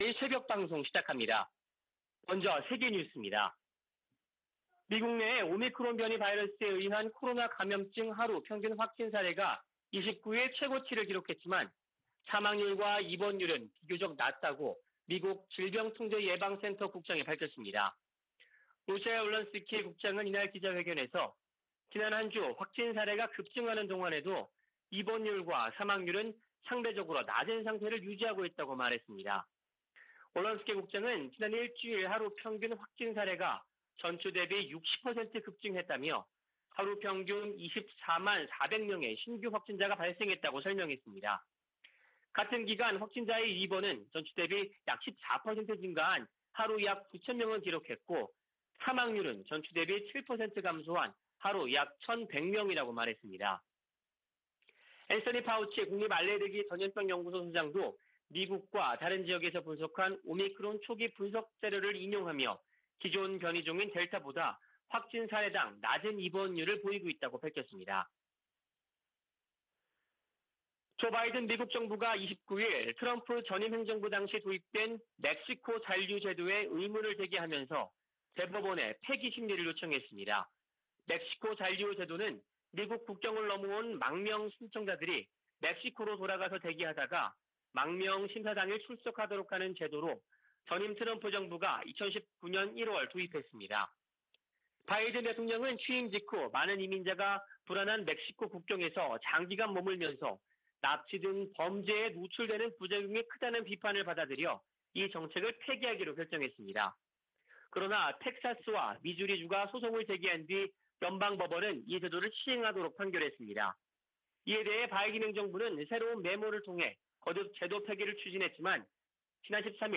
VOA 한국어 '출발 뉴스 쇼', 2021년 12월 31일 방송입니다. 미 국방부는 다양한 안보 문제에 대해 한국 동맹군과 정기적으로 합동 계획을 수립하고 있다고 밝혔습니다. 올해 김정은 북한 국무위원장은 이례적으로 군사훈련 관련 공개 활동을 하지 않은 것으로 나타났습니다. 북한이 지난해 1월부터 코로나바이러스 유입을 막겠다며 국경을 전면 봉쇄한 가운데, 아직 재개방 조짐은 보이지 않고 있습니다.